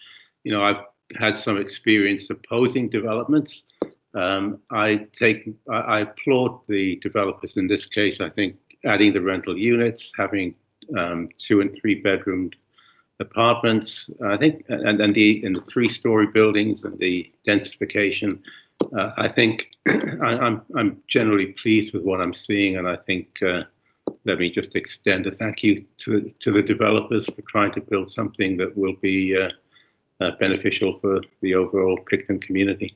Tuesday night the county hosted a virtual public information session to detail the third iteration of the housing development near Picton.
Consequently, another man applauded the developers for the changes that they’ve enacted since the last iteration was presented in February.